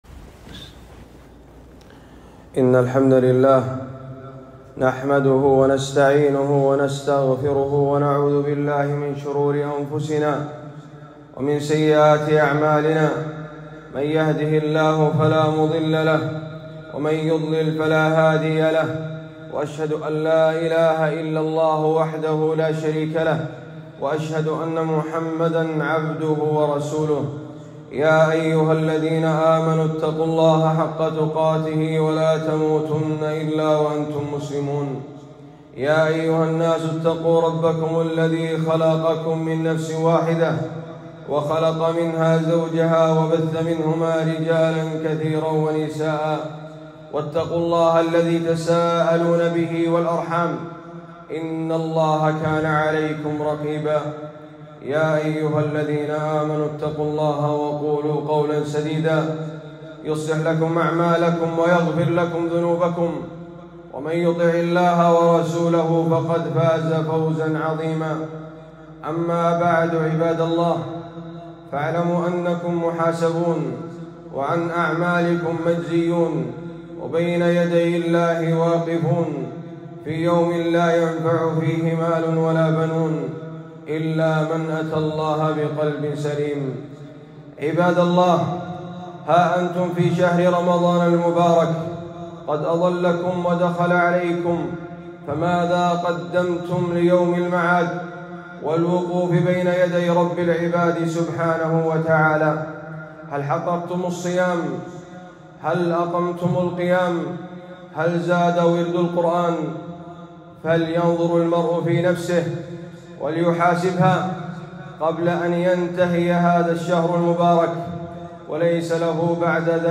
خطبة - فضائل الصدقة وتفريج الكربات 5-9-1445